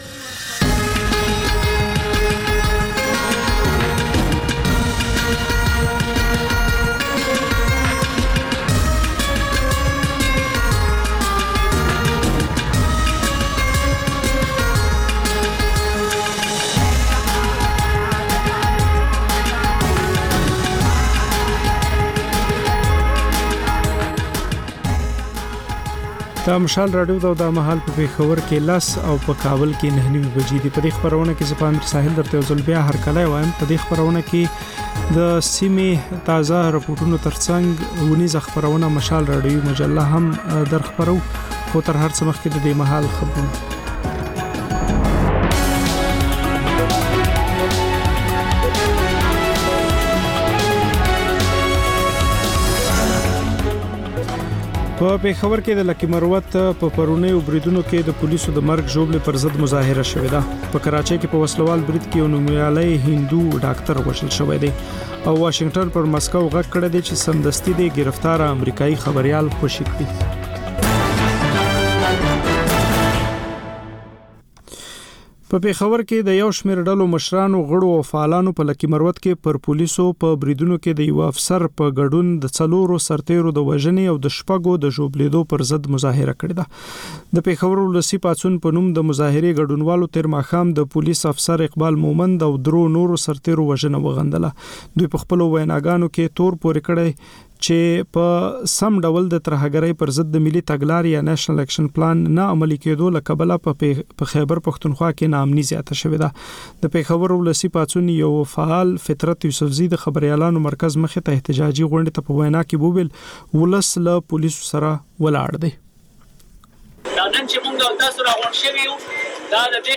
په دې خپرونه کې تر خبرونو وروسته بېلا بېل رپورټونه، شننې او تبصرې اورېدای شﺉ. د خپرونې په وروستیو پینځلسو دقیقو یا منټو کې یوه ځانګړې خپرونه خپرېږي.